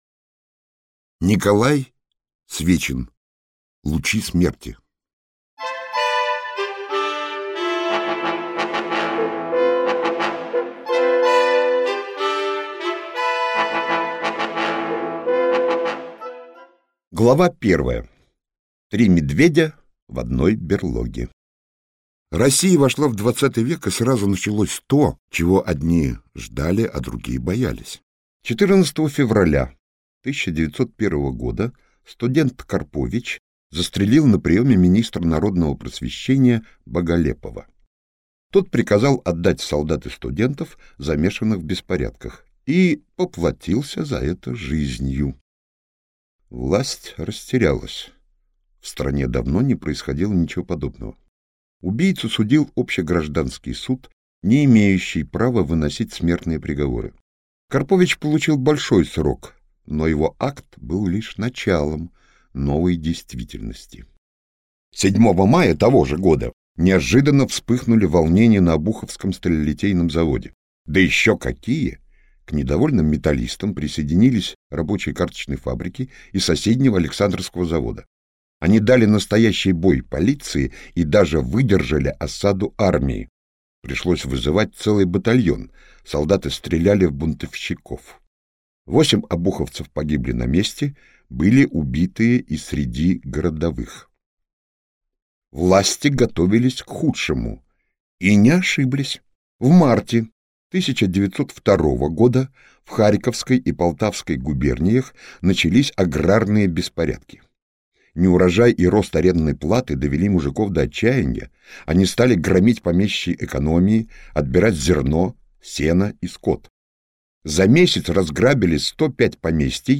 Аудиокнига Лучи смерти - купить, скачать и слушать онлайн | КнигоПоиск